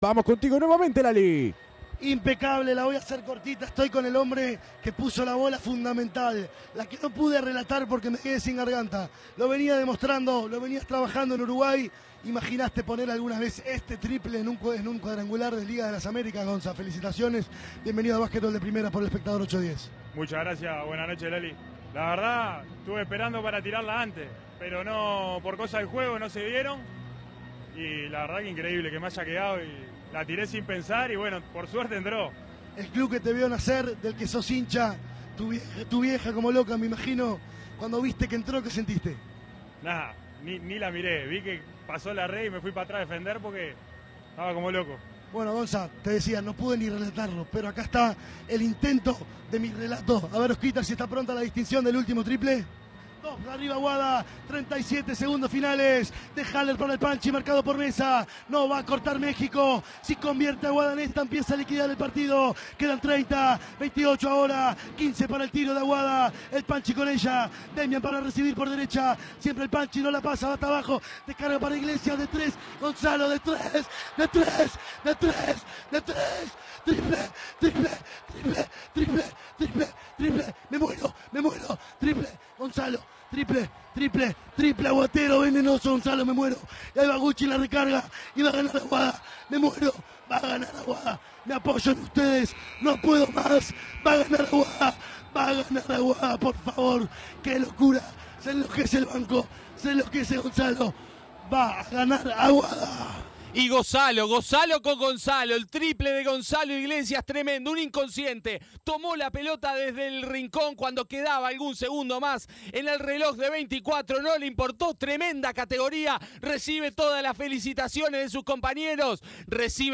VIVO